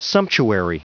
Prononciation du mot sumptuary en anglais (fichier audio)
Prononciation du mot : sumptuary